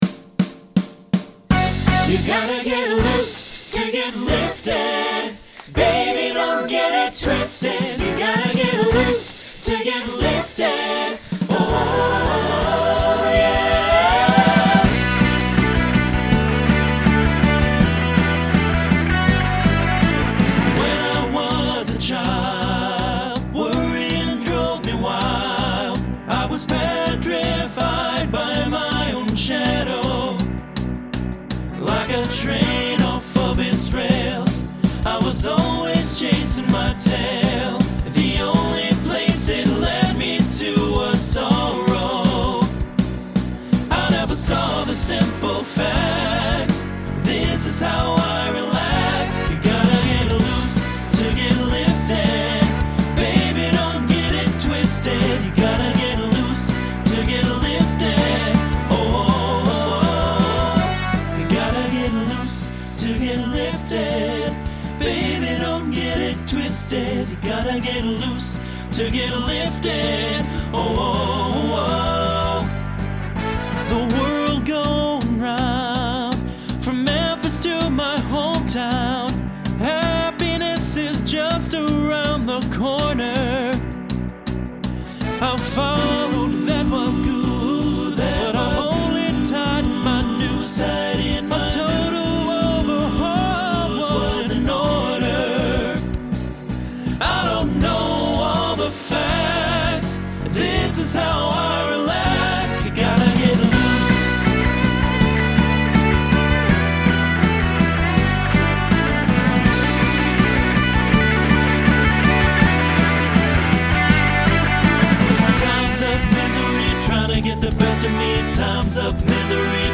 Genre Rock
Transition Voicing TTB